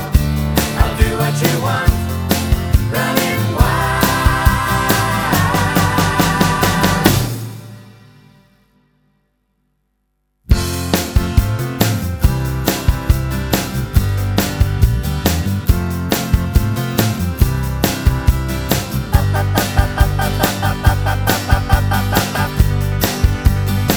One Semitone Down Pop (1970s) 4:15 Buy £1.50